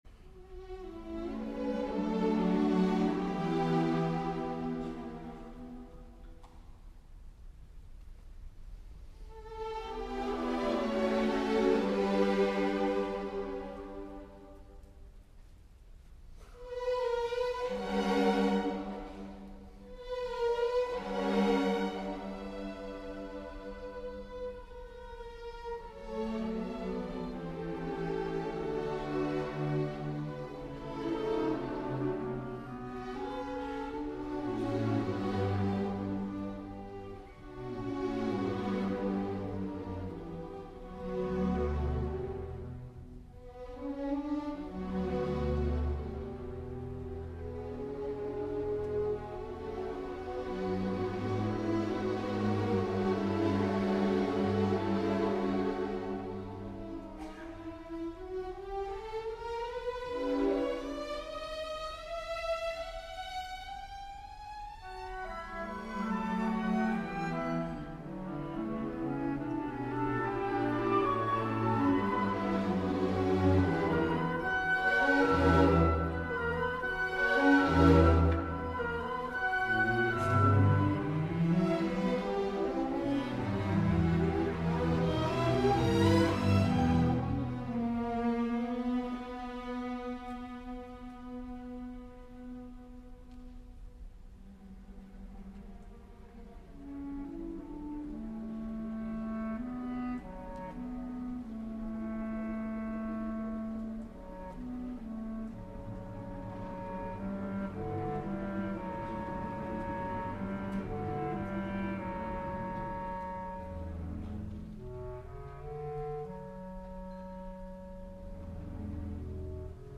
Hier gaat het onder andere om de sfeer die vooral door de strijkers wordt neergezet.